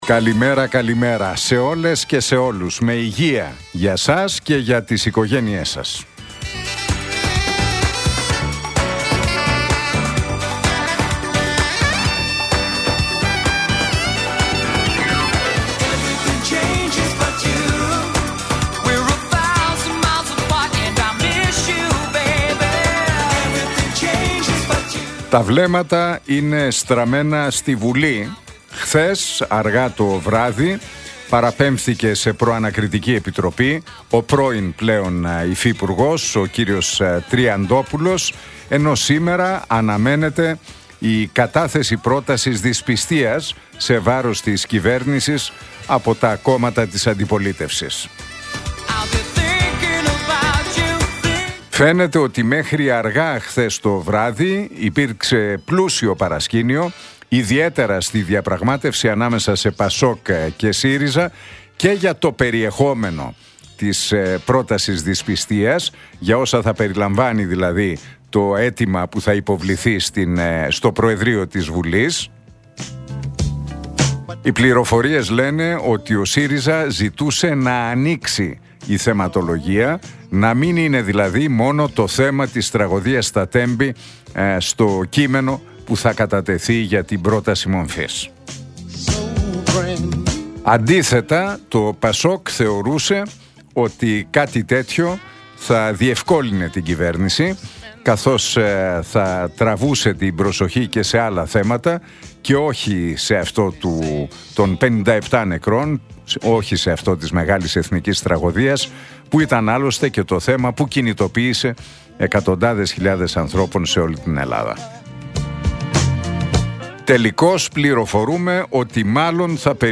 Ακούστε το σχόλιο του Νίκου Χατζηνικολάου στον ραδιοφωνικό σταθμό RealFm 97,8, την Τετάρτη 5 Μαρτίου 2025.